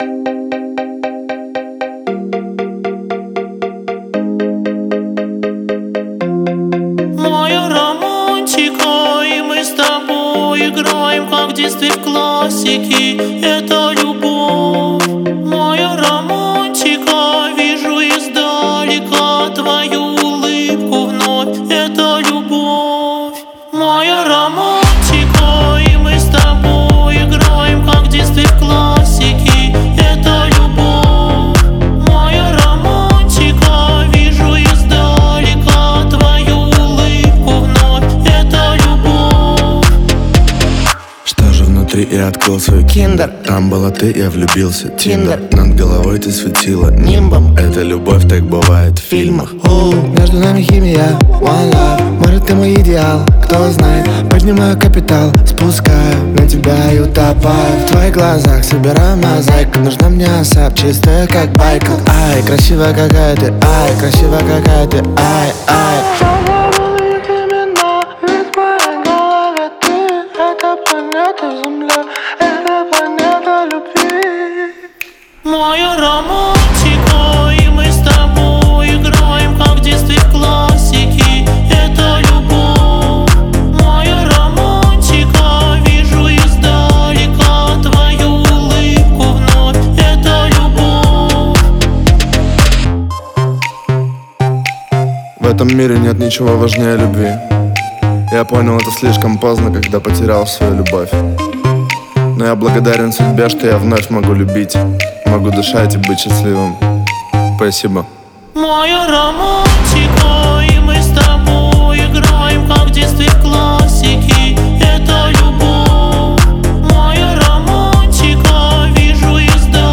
это мелодичный трек в жанре хип-хоп с элементами R&B